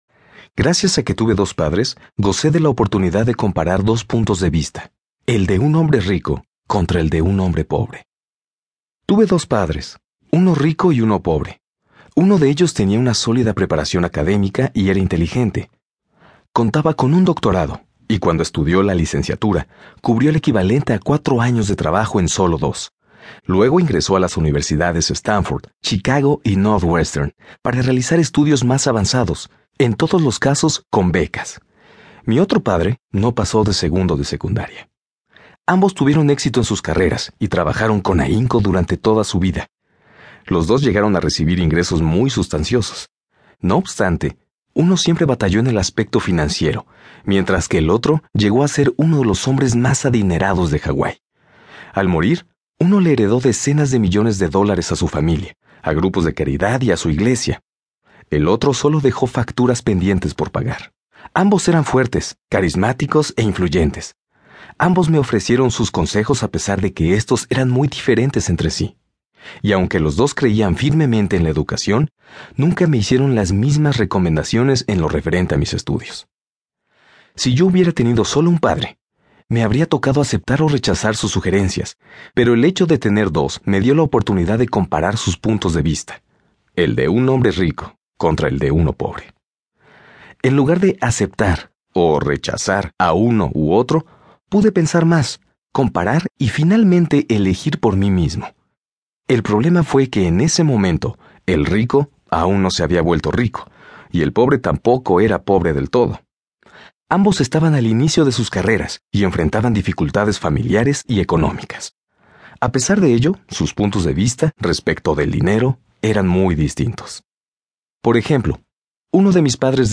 👇 MIRA LA LISTA COMPLETA Y ESCUCHA LA MUESTRA DE CADA AUDIOLiBRO 👇